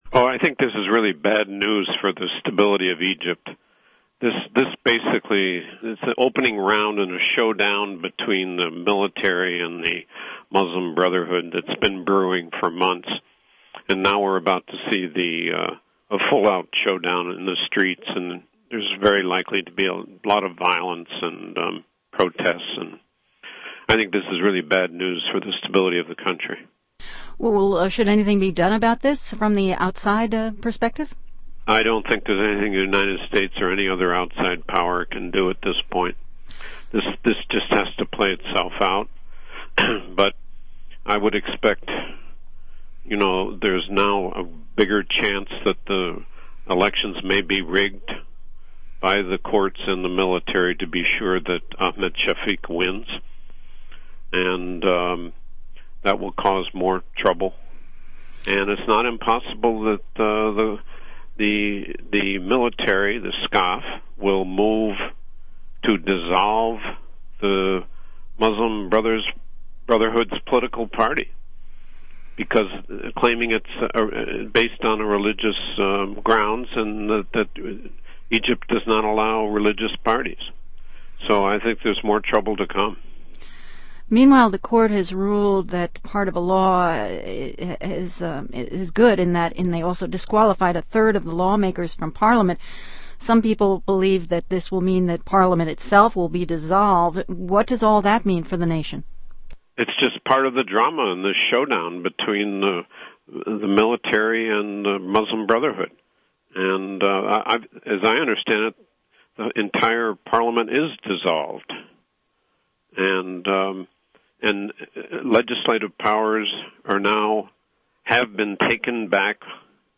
Q&A on Egyptian court ruling and what it means for political scene